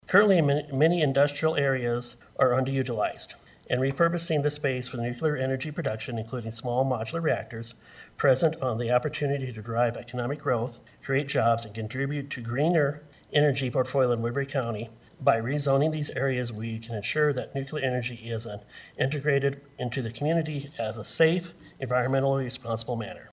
AMONG THE SUPPORTERS WAS A SPOKESMAN FOR BUILDING TRADE WORKERS IN WESTERN IOWA: